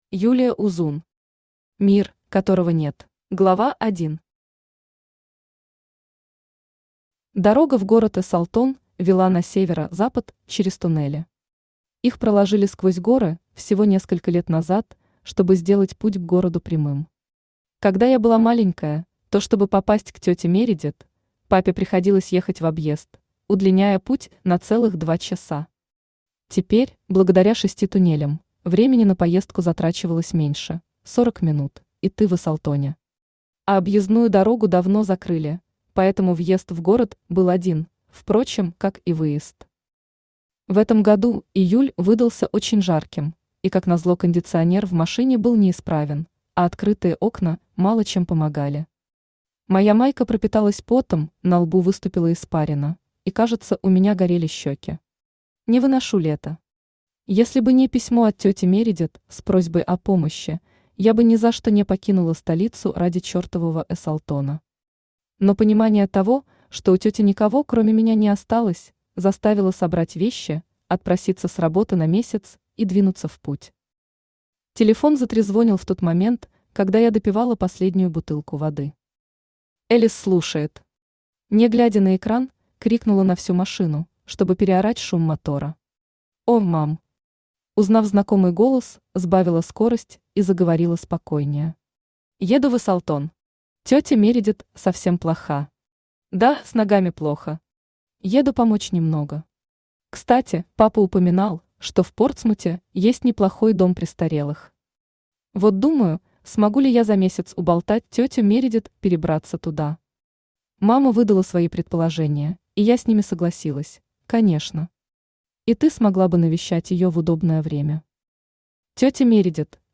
Aудиокнига Мир, которого нет Автор Юлия Узун Читает аудиокнигу Авточтец ЛитРес.